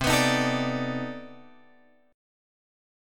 C Minor Major 13th